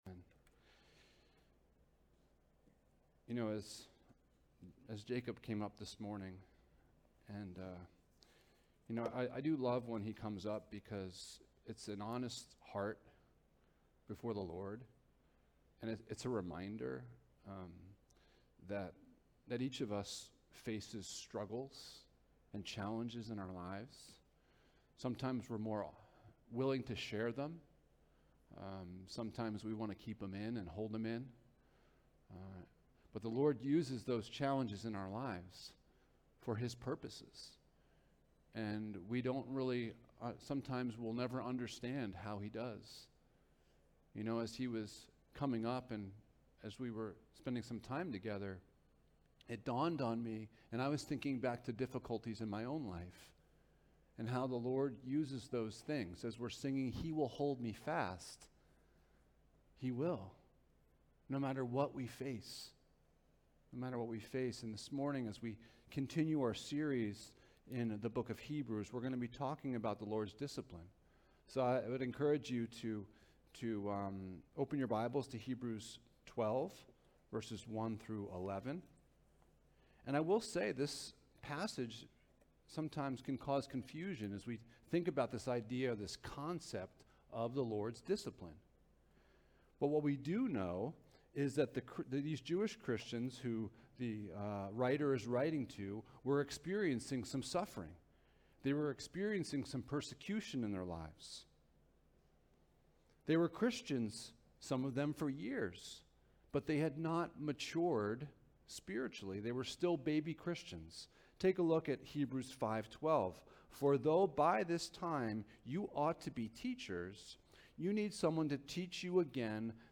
Jesus is Better Passage: Hebrews 12: 1-11 Service Type: Sunday Morning « Cracks in the Foundation of our Faith The Lord’s Discipline